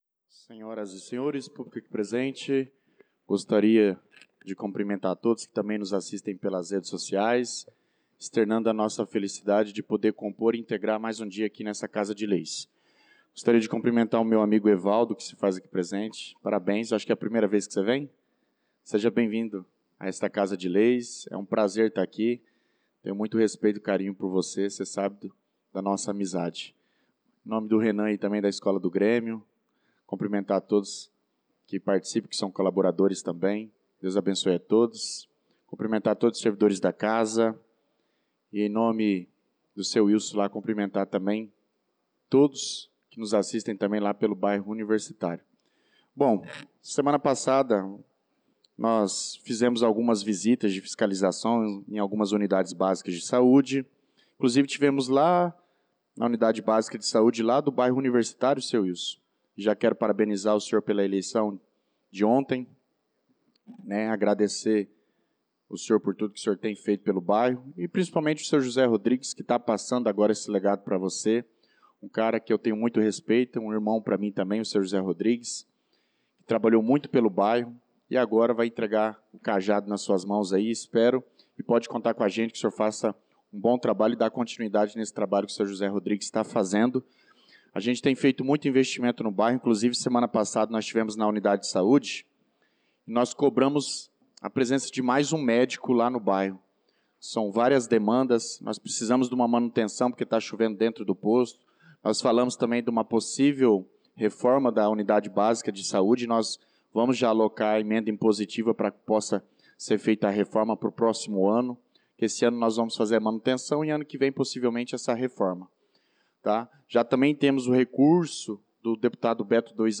Pronunciamento do vereador Douglas Teixeira na Sessão Ordinária do dia 28/04/2025